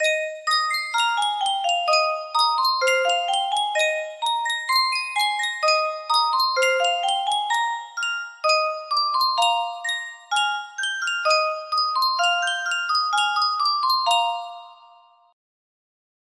Yunsheng Music Box - The East is Red Y837 music box melody
Full range 60